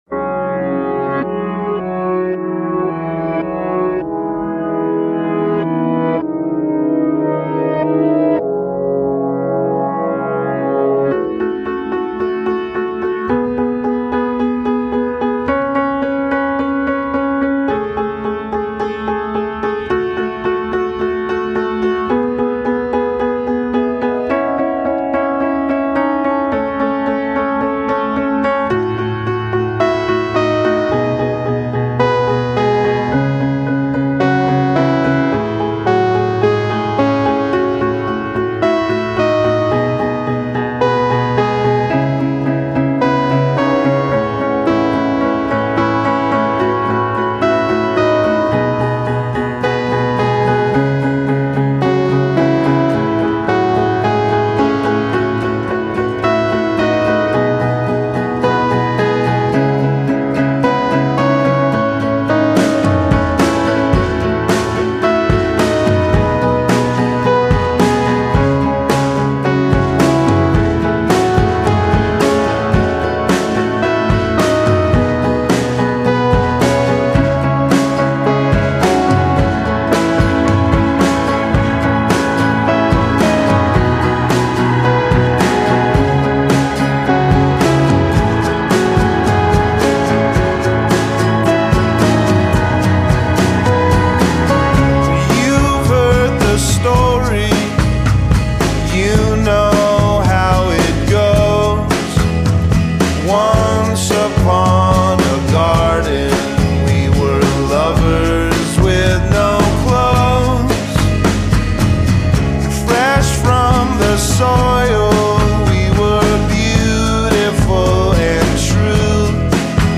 in a voice shot through with disappointment and doubt.